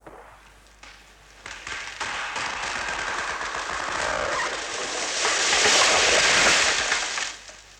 Звуки падающих деревьев
Шум природы: дерево падает в отдалении